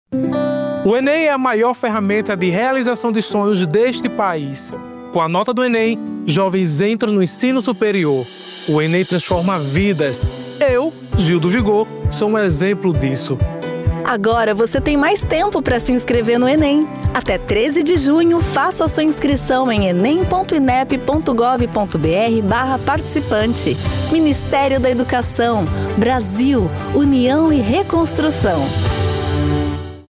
Spots